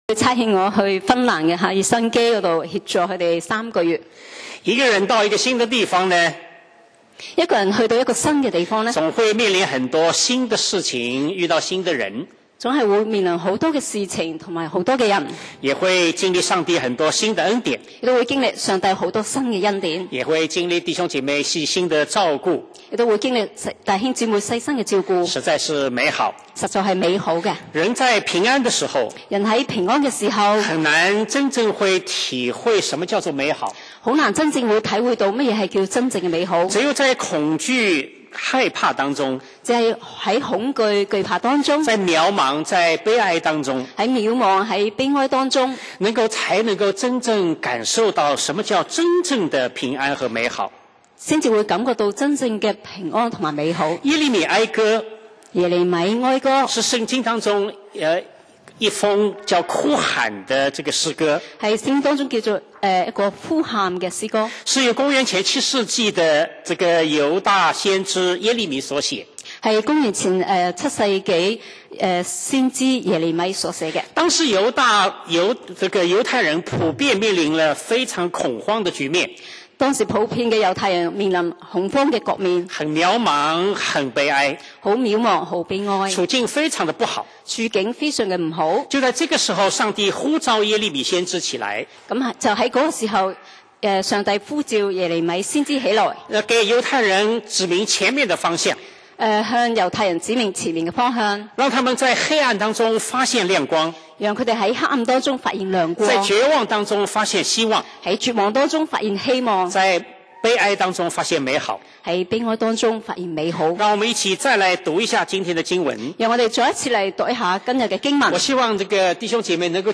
講道 Sermon 題目 Topic：回应上帝美好的爱 經文 Verses：哀3：22-26. 22 我们不至消灭，是出于耶和华诸般的慈爱。